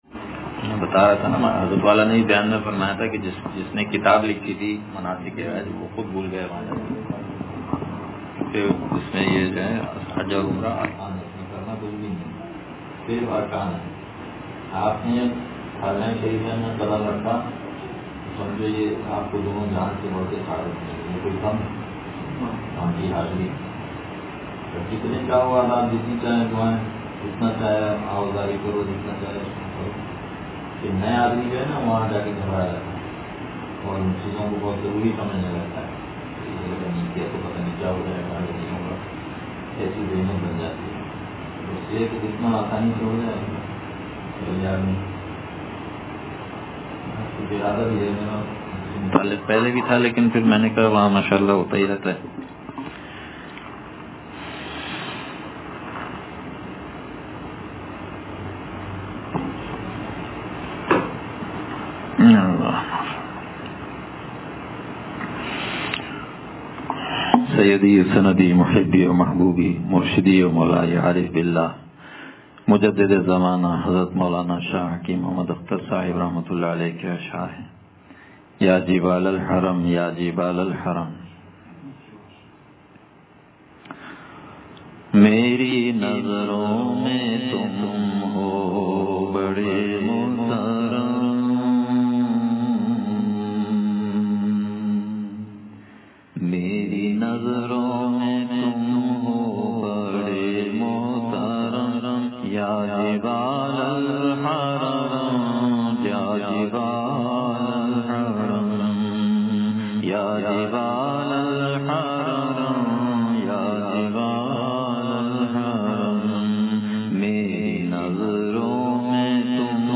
یا جبال الحرم یا جبال الحرم – حجِ بیت اللہ سے متعلق بیان – دنیا کی حقیقت – نشر الطیب فی ذکر النبی الحبیب صلی اللہ علیہ وسلم